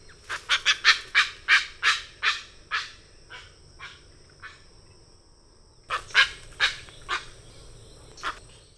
"Yellow-crowned Night-Heron"
Nyctanassa violacea
yaboa-comun.wav